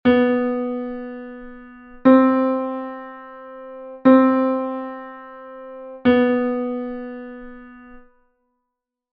Exercise 2 : low B-C diatonic semitone exercise.
semitono_diatonico_si-do_-_grave.mp3